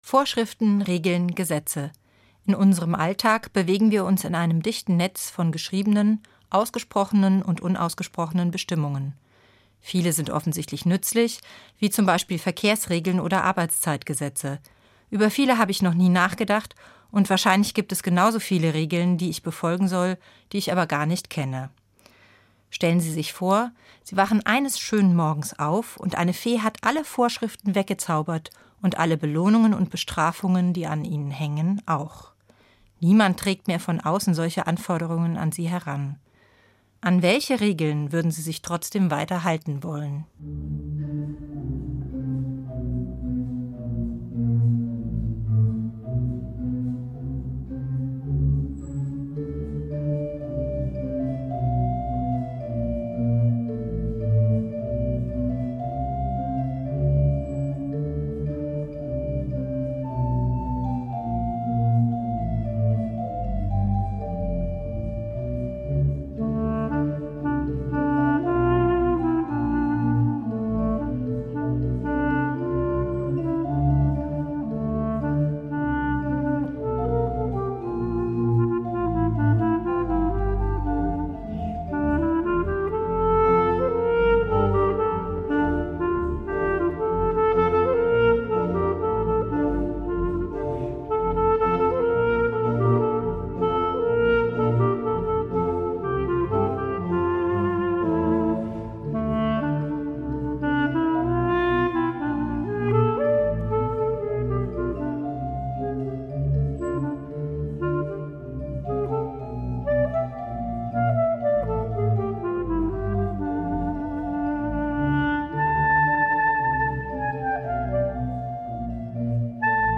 MORGENFEIER